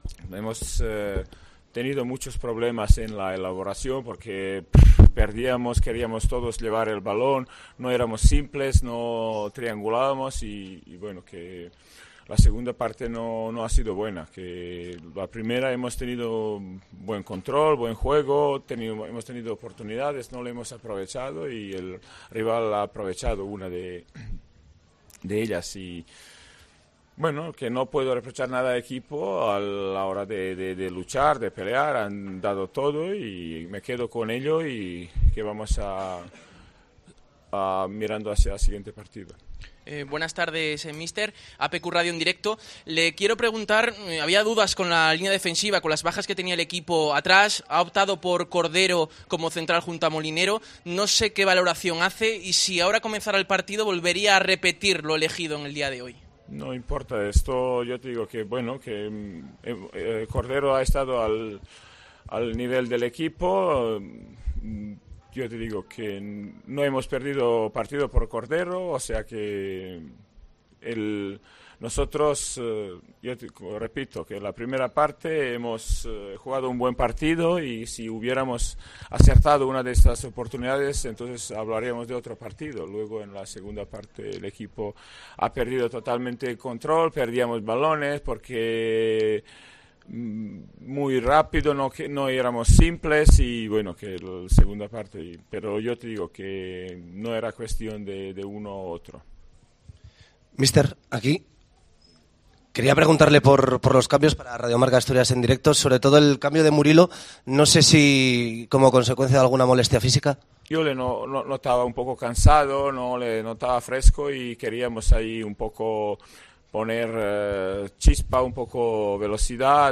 POSTPARTIDO
Escucha aquí las palabras del entrenador del Sporting de Gijón, Miroslav Djukic, tras la derrota en El Toralín 1-0 ante la Deportiva Ponferradina